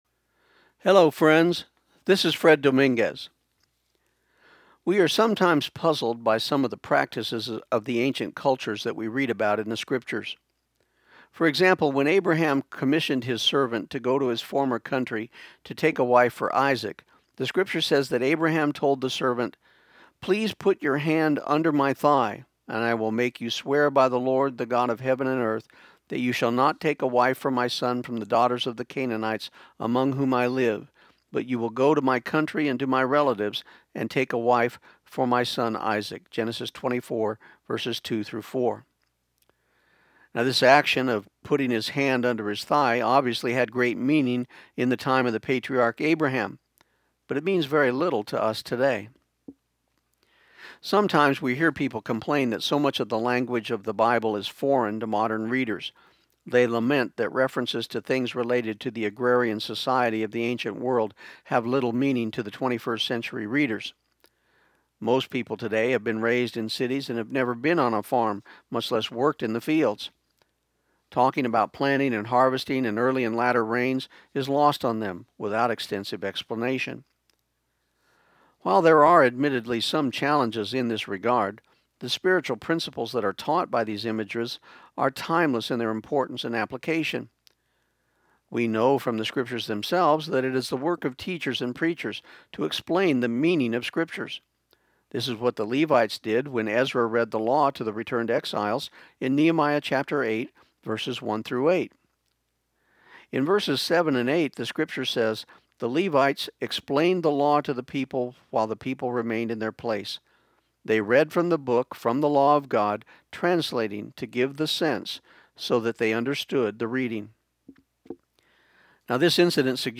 This program aired on KIUN 1400 AM in Pecos, TX on December 26, 2014.